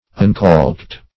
uncaulked - definition of uncaulked - synonyms, pronunciation, spelling from Free Dictionary